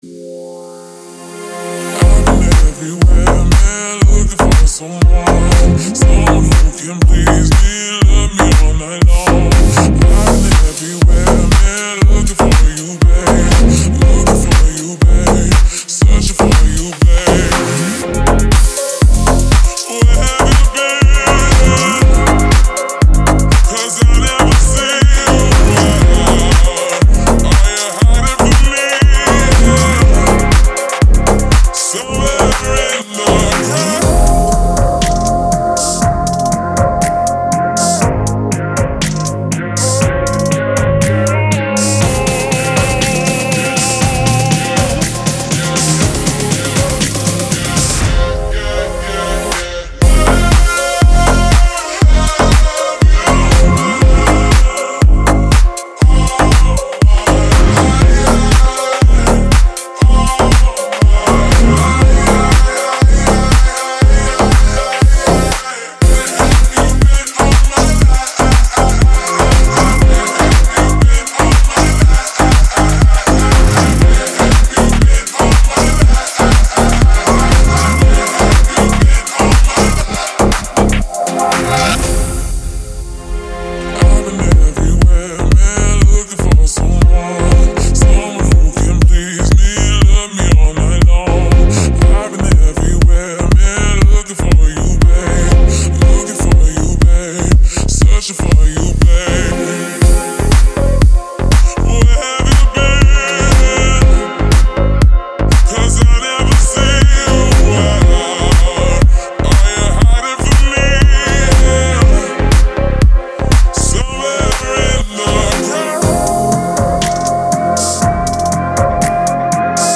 яркие и эмоциональные вокалы